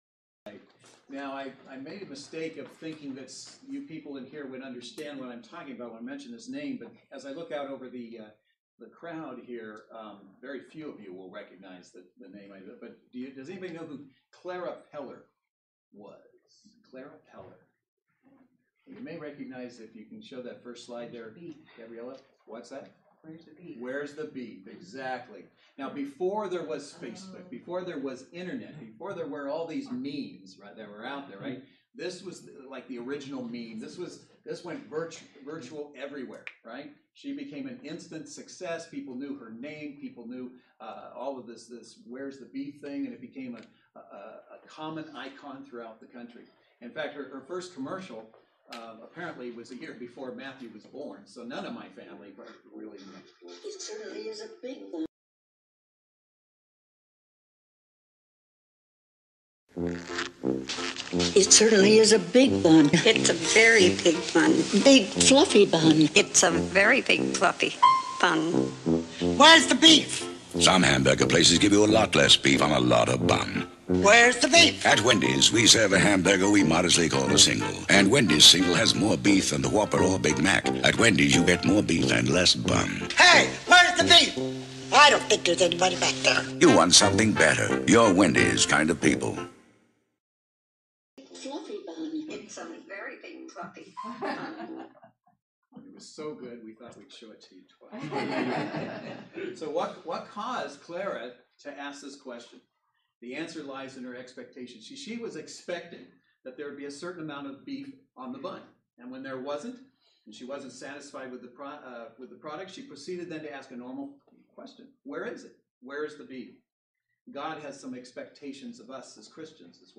Passage: Mark 11:20-26 Service Type: Saturday Worship Service Bible Text